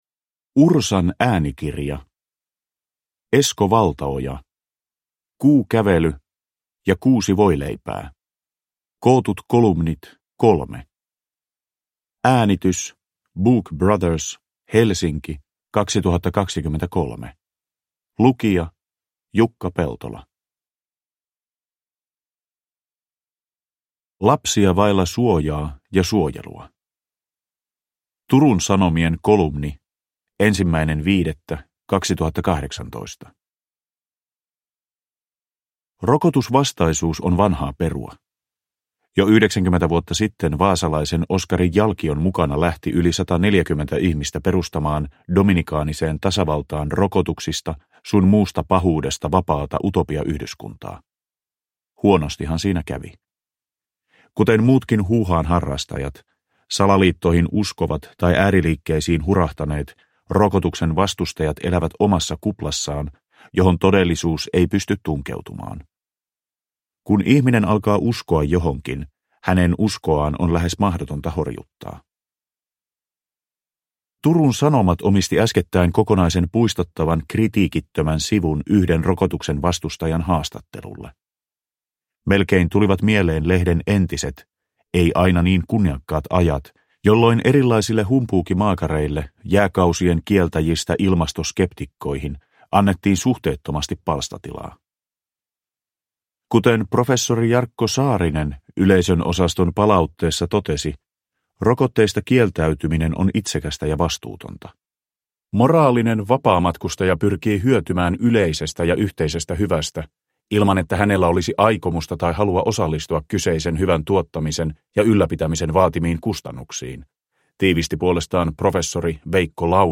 Kuukävely ja kuusi voileipää – Ljudbok
Uppläsare: Jukka Peltola